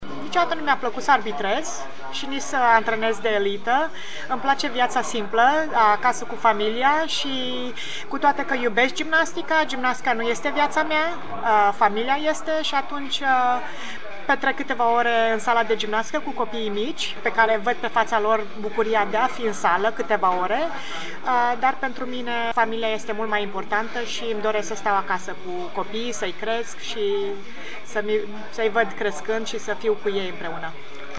Stabilită în SUA din 1991, Daniela Silivaş (foto, în centru), una dintre cele mai mari gimnaste din istorie, s-a întors acasă, la Deva, la final de an și a acceptat să vorbească, pentru Radio Timișoara, despre ce a însemnat 2019 pentru gimnastica din România și despre cum crede vor arăta Jocurile Olimpice de la Tokyo, din acest an.